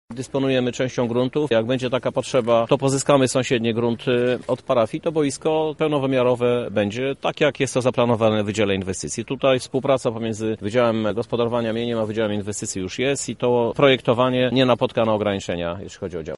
– dodaje Żuk.